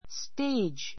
stéidʒ